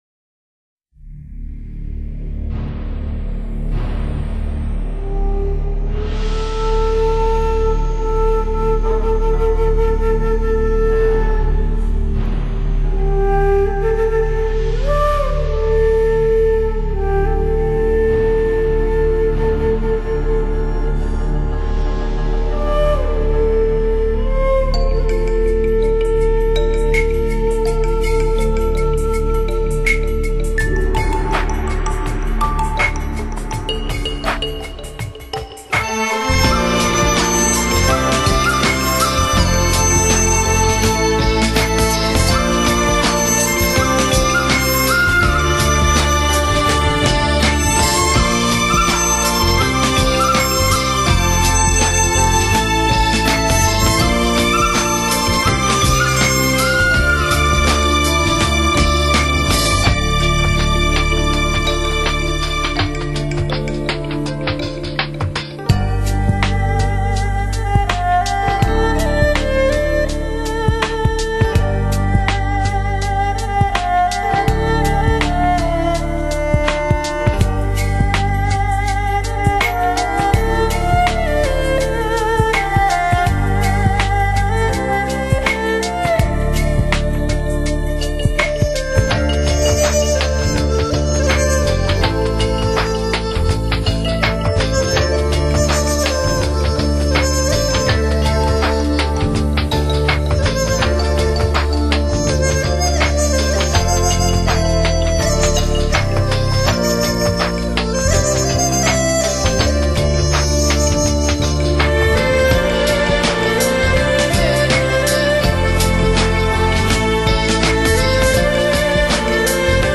所有收录的乐曲均为原创，民间乐器自然古朴的原音与现代音乐表现手法相结合，揉合出耐人寻味的听觉效果。